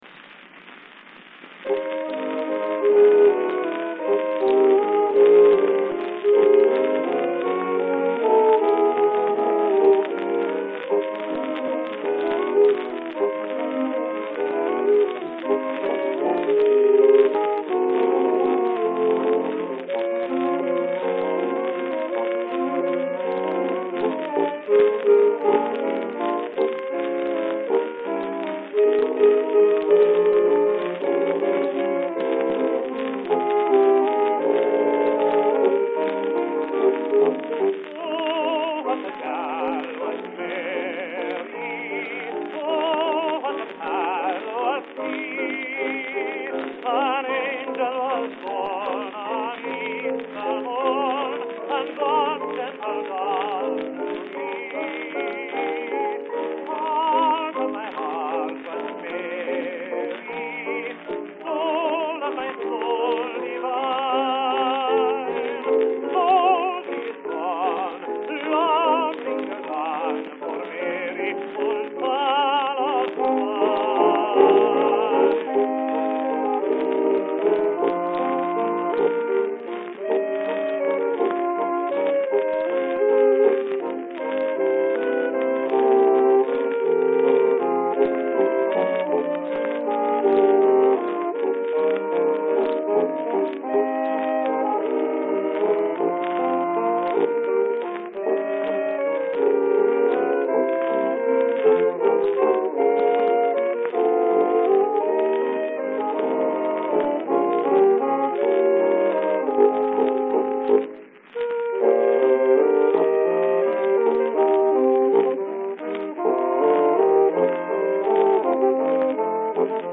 Incidental Chorus